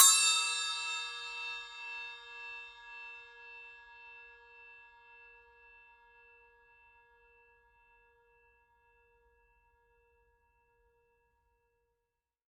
7" Cup Chime
7_cup_chime_edge.mp3